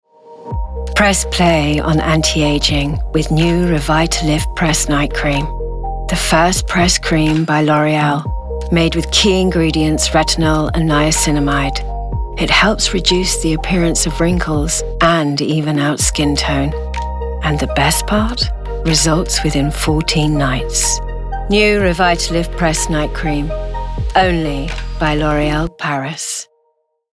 Voice Reel
L'Oreal - Soothing, Relaxed, Assured,